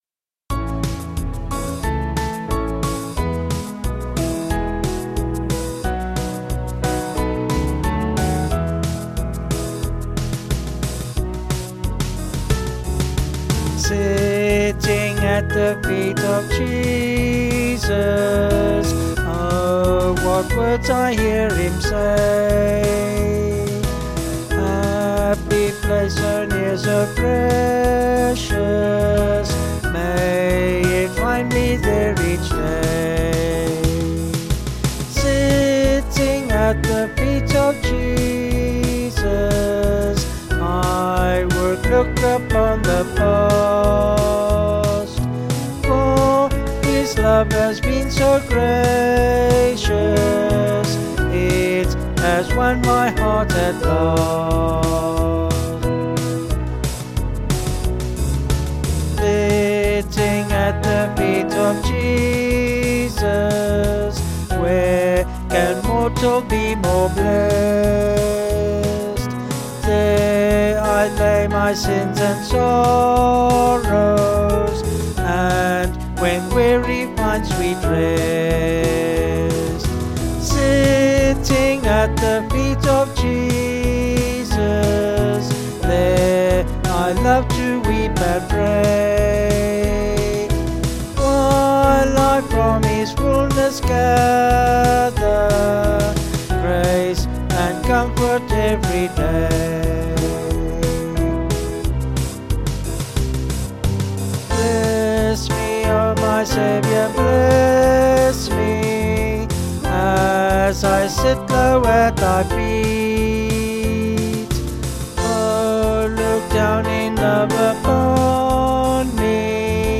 Vocals and Band   263.7kb Sung Lyrics